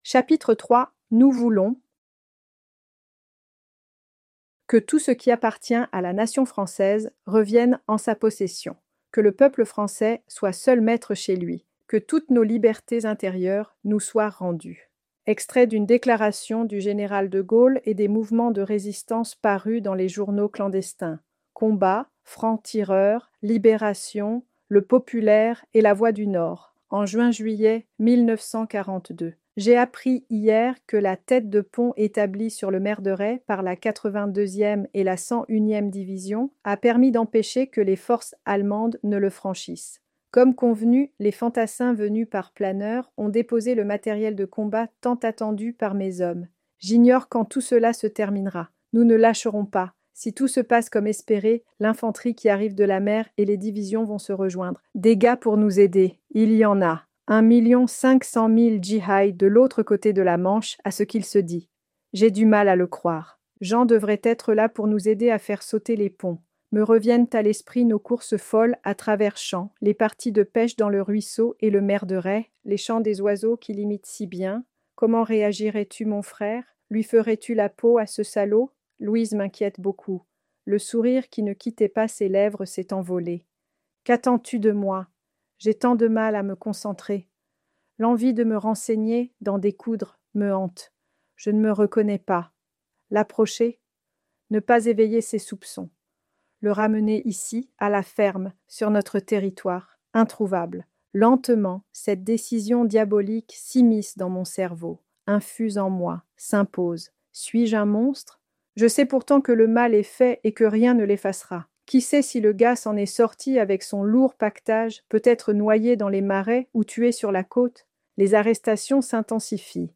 Ce livre audio a été enregistré en utilisant une synthèse vocale de la voix de l'autrice.